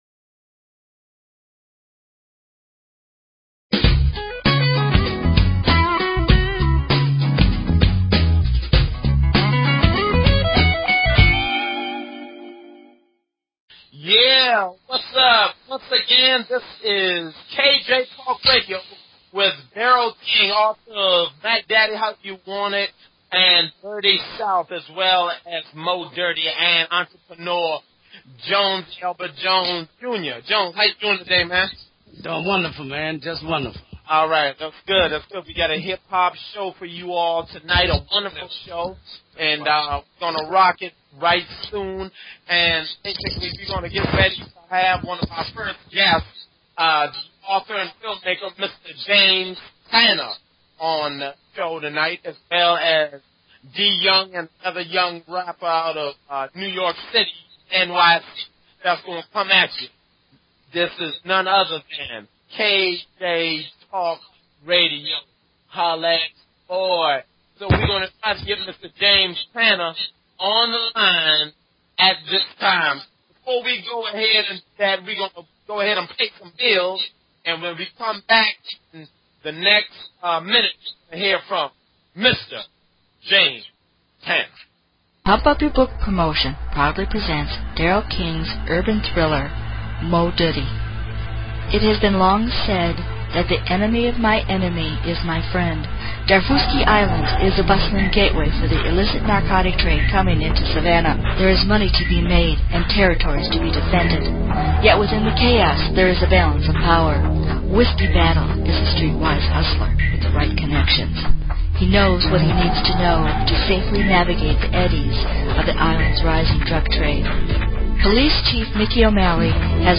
Talk Show Episode, Audio Podcast, KJ_Talk_Radio and Courtesy of BBS Radio on , show guests , about , categorized as
KJ Talk radio is an un opinionated, and open forum which provides a platform for a wide variety of guests, and callers alike.